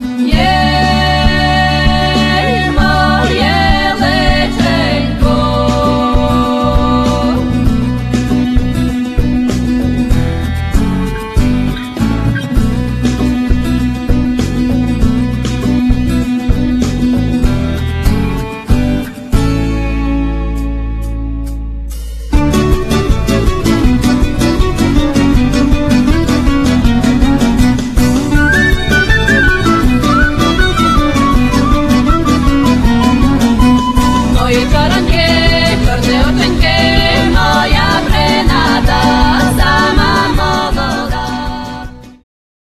akordeon, sopiłki, fujara słowacka, tamburyn, ¶piew
basbałałaja, drumla, instrumenty perkusyjne, ¶piew
gitara, mandolina
skrzypce
zestaw perkusyjny